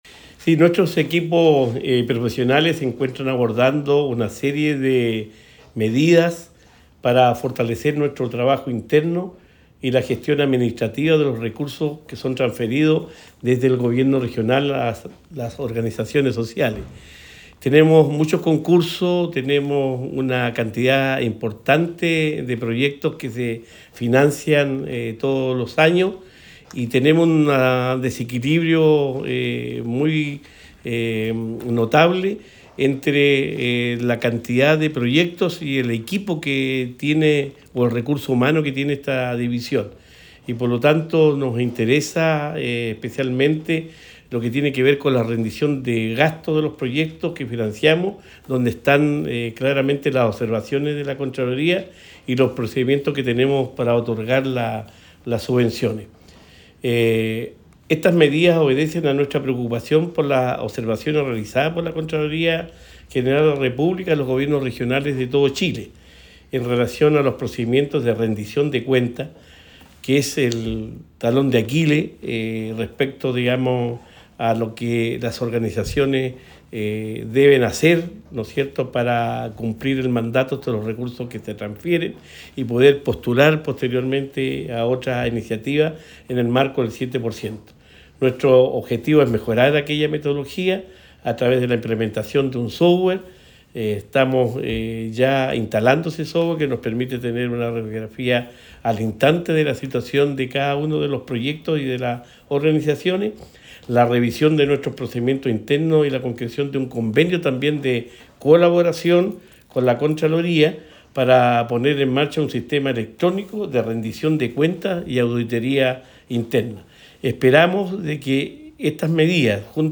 Cuña_Gobernador-Regional_Plan-de-Medidas_7-nov-1.mp3